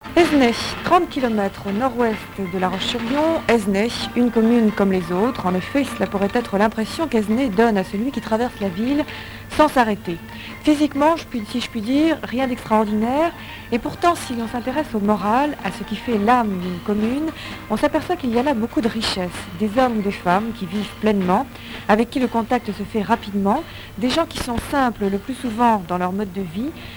Musique, bal, émission de radio
Catégorie Témoignage